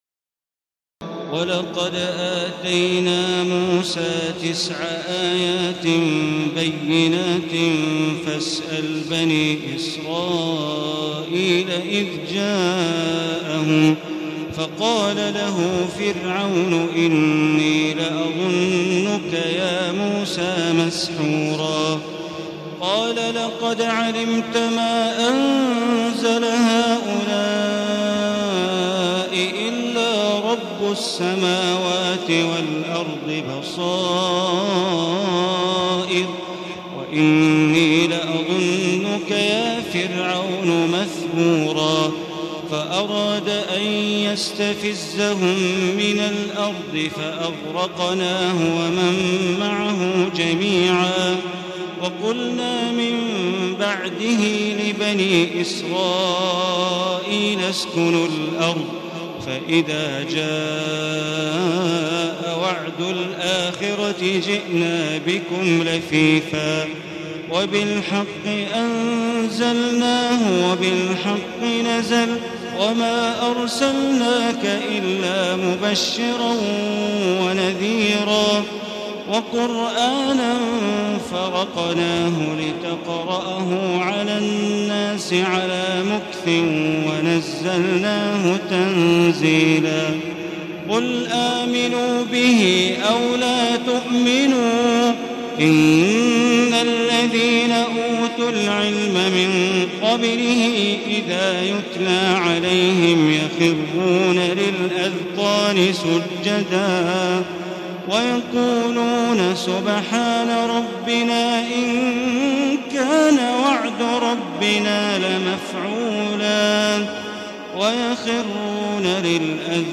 تراويح الليلة الخامسة عشر رمضان 1435هـ من سورتي الإسراء (101-111) والكهف (1-82) Taraweeh 15 st night Ramadan 1435H from Surah Al-Israa and Al-Kahf > تراويح الحرم المكي عام 1435 🕋 > التراويح - تلاوات الحرمين